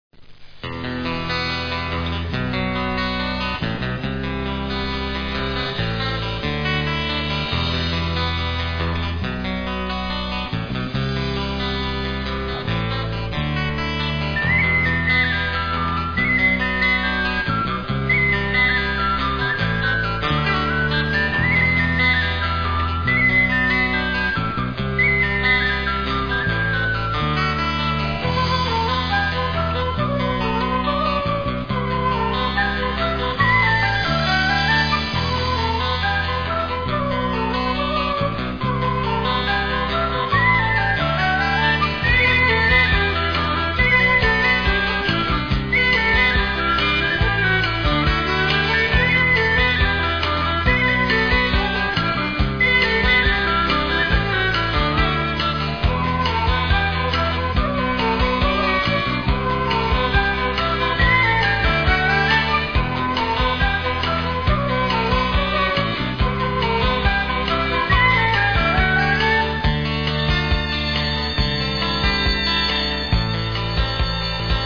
Musica Folk de Cantabria